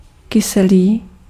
Ääntäminen
US GenAm: IPA : /ˈsaʊ(ə)ɹ/